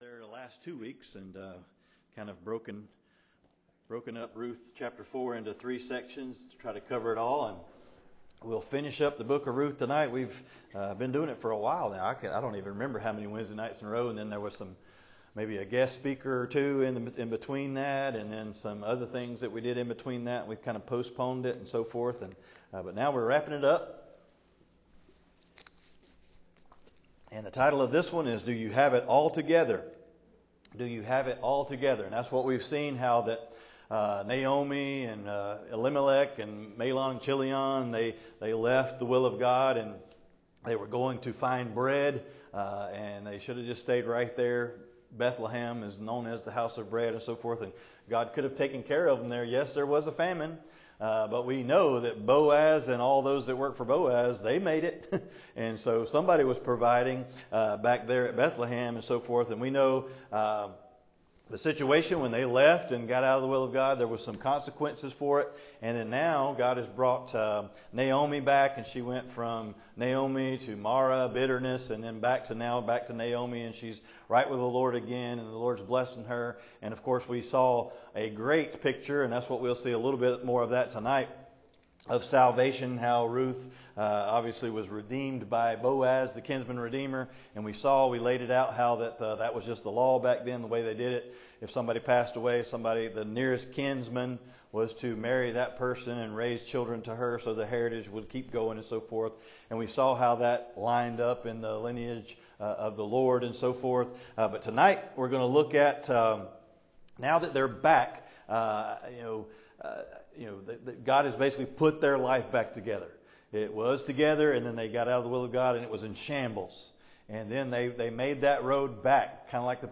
Passage: Ruth 4 Service Type: Wednesday Night